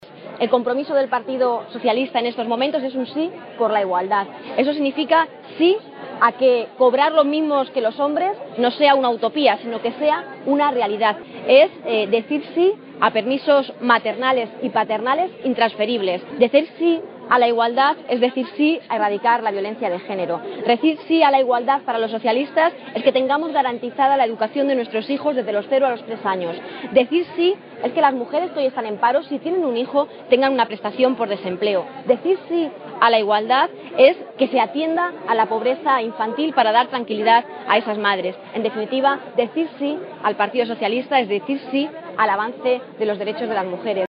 Encuentro con mujeres en Miguelturra
Cortes de audio de la rueda de prensa